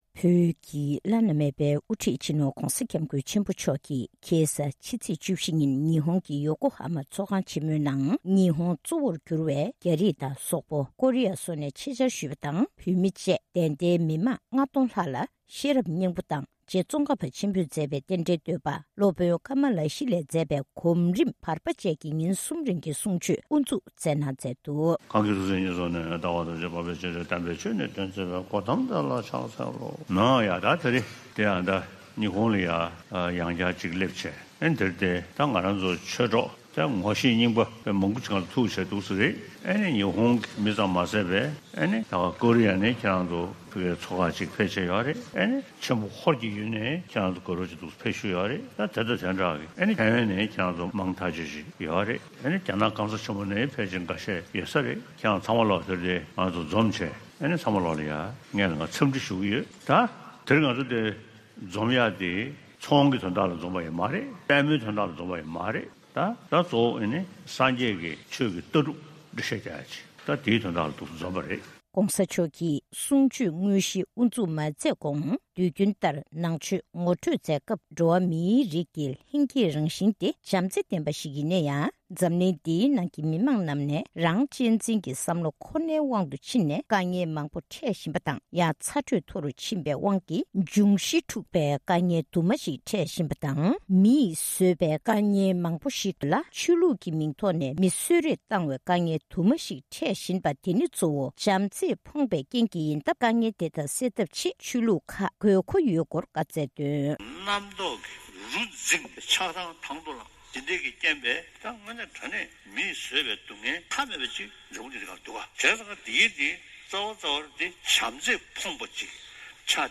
༧གོང་ས་མཆོག་གིས་ཉི་ཧོང་དུ་ཆོས་འབྲེལ་སྐབས། ༢༠༡༨།༡༡།༡༤ ༧གོང་ས་མཆོག་གིས་ཉི་ཧོང་དུ་ཆོས་འབྲེལ་སྐབས། ༢༠༡༨།༡༡།༡༤
སྒྲ་ལྡན་གསར་འགྱུར།